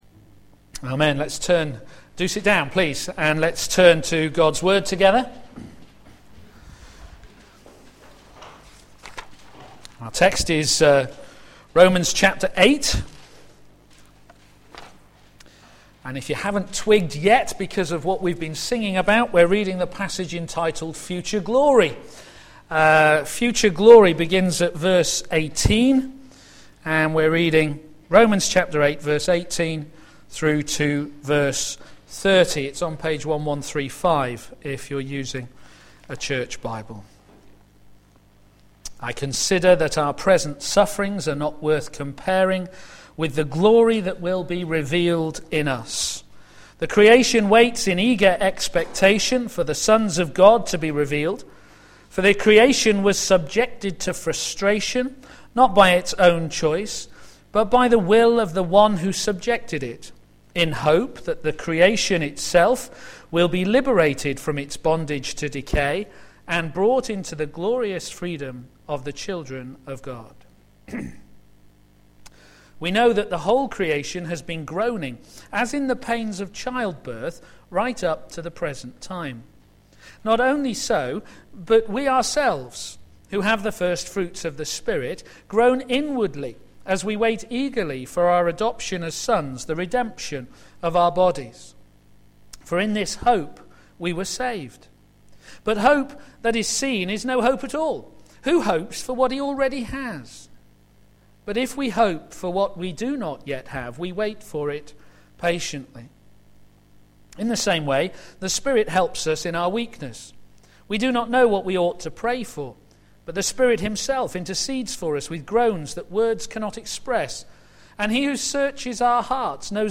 p.m. Service
God's assurance of eternity given by the Spirit of Glory Sermon